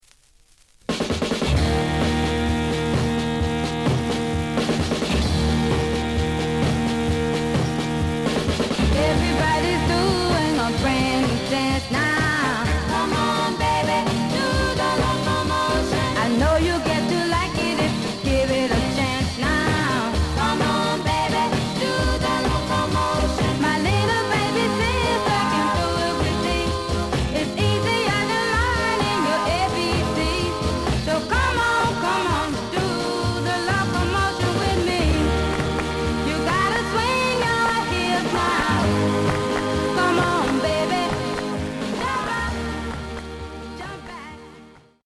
The audio sample is recorded from the actual item.
●Genre: Rhythm And Blues / Rock 'n' Roll
Noticeable noise on A side.